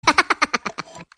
minion-short-laugh_24915.mp3